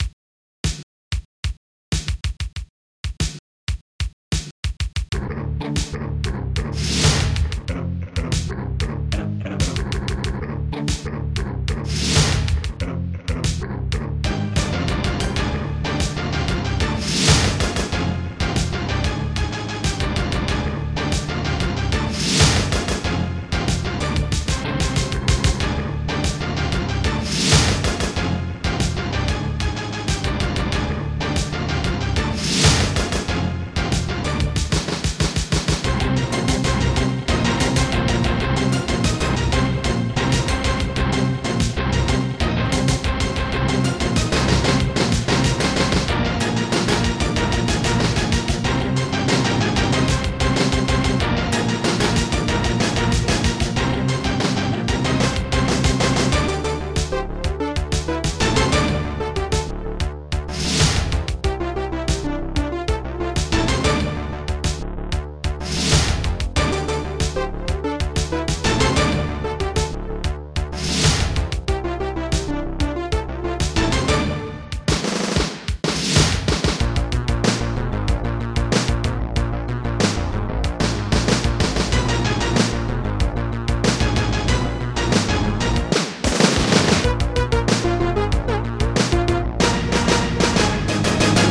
Protracker Module  |  1993-08-17  |  154KB  |  2 channels  |  44,100 sample rate  |  1 minute, 31 seconds
Protracker and family
St-02:bassdrum5
St-02:snaredrum8
St-01:korgbass
St-01:hallbrass
St-02:p-bongo